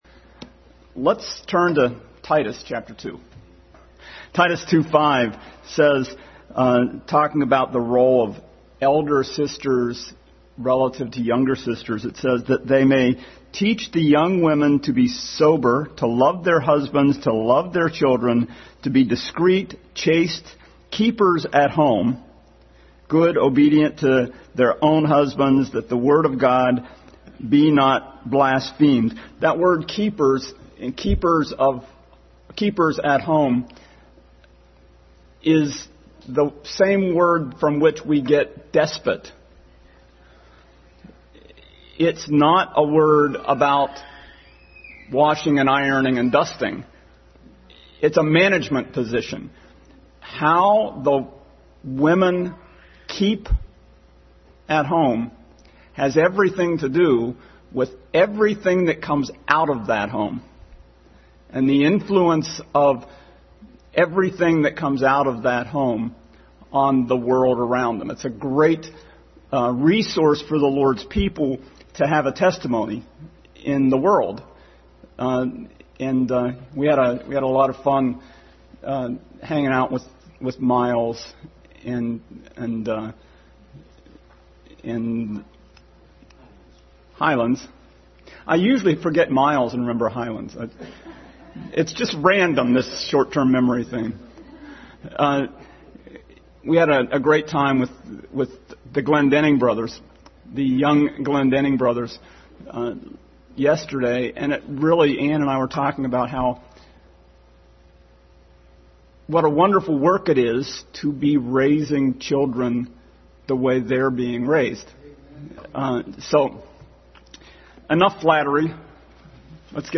3:8 Passage: Titus 2:11-3:8, Exodus 19, Revelation 50 Service Type: Sunday School